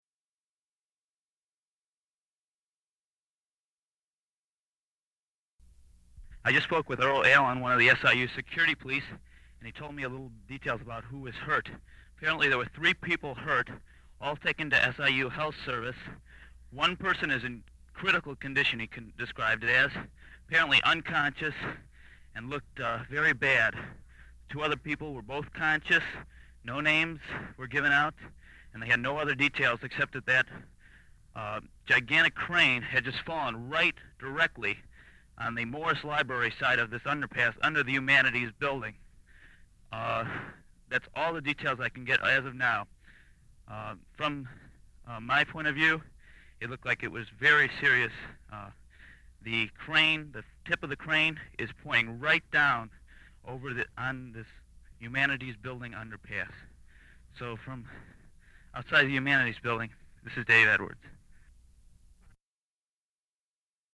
I called him in his dorm room, and he delivered a chilling report, ending with “I could see the blood running down the sidewalk.”